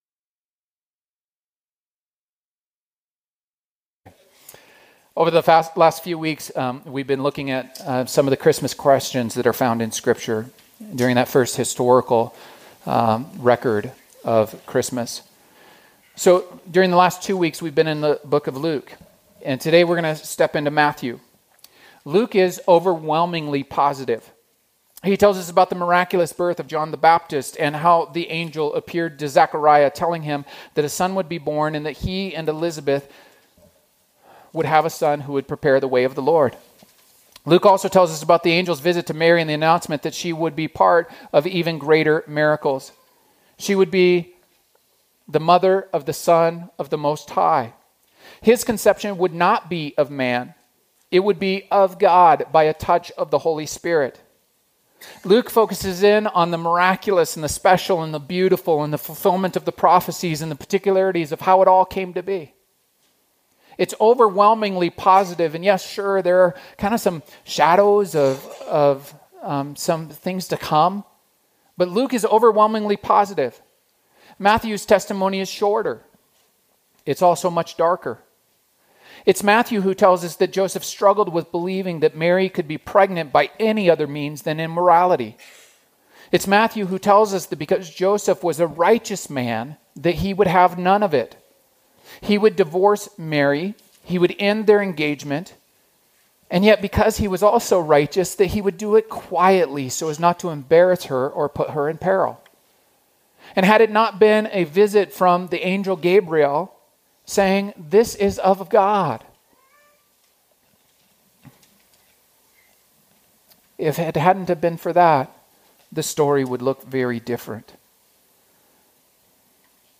A message from the series "Christmas Questions."